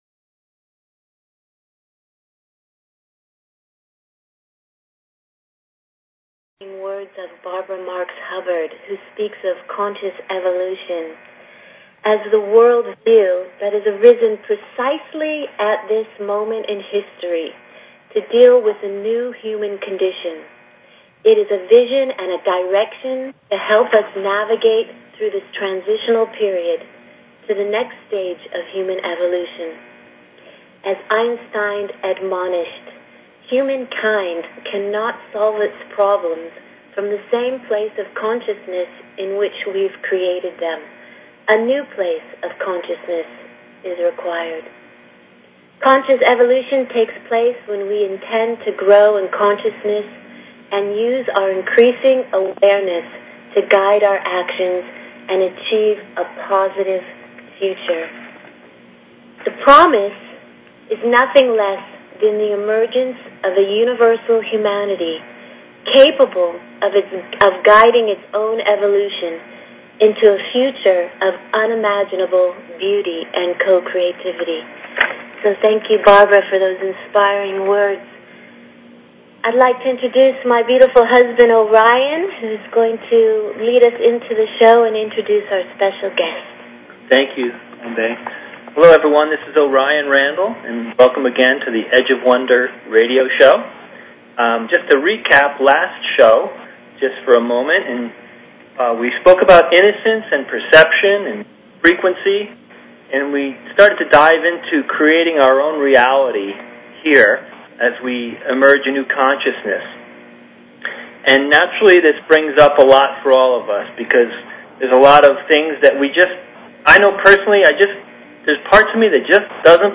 Talk Show Episode, Audio Podcast, Edge_of_Wonder_Radio and Courtesy of BBS Radio on , show guests , about , categorized as